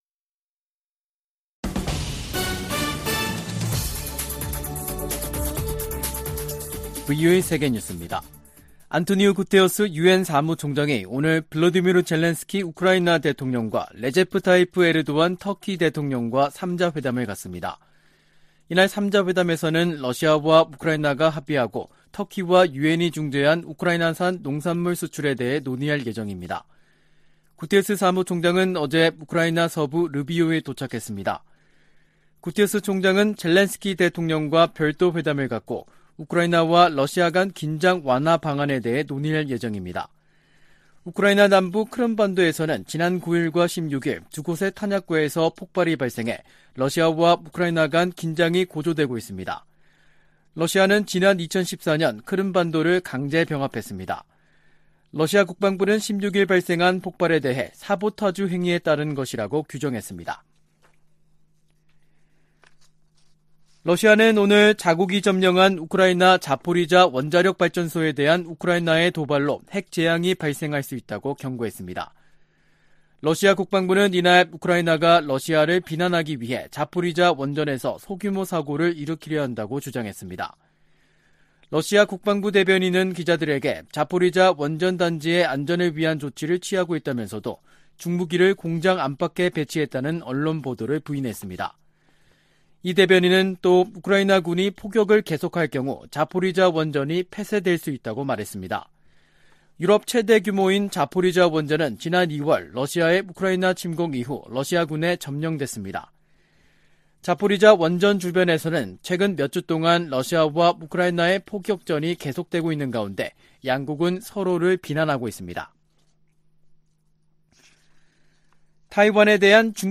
VOA 한국어 간판 뉴스 프로그램 '뉴스 투데이', 2022년 8월 18일 3부 방송입니다. 한국 정부가 ‘담대한 구상’과 관련한 구체적인 대북 메시지를 발신하고 북한이 수용할 수 있는 여건을 조성해나갈 것이라고 밝혔습니다. 이종섭 한국 국방부 장관이 서울 국방부 청사에서 폴 나카소네 미국 사이버사령관을 접견하고 북한 등 사이버 위협에 대해 협력 대응하기로 했습니다. 미 국무부는 북한의 도발적 행동에 변화가 없다면 제재는 계속될 것이라고 밝혔습니다.